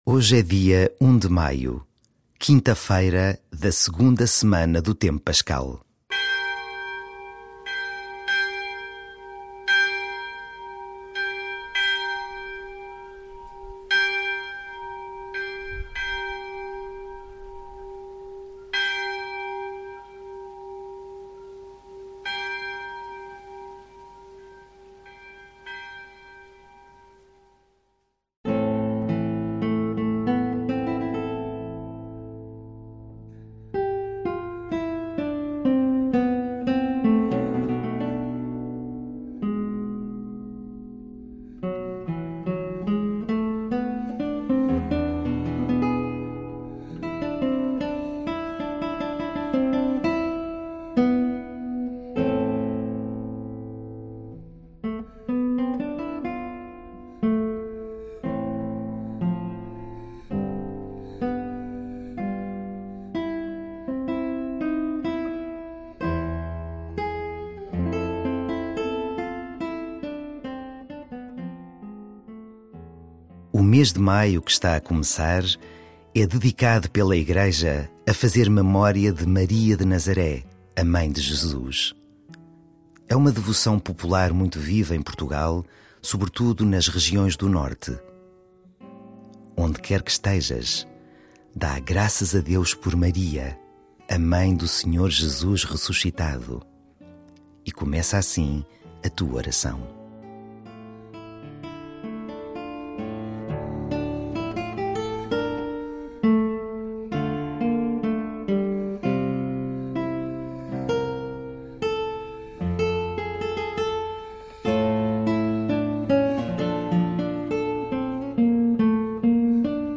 Esta noite juntamos-nos mais uma vez na capela! Tivemos hoje junto de nós, para além de Jesus, também a musica da viola!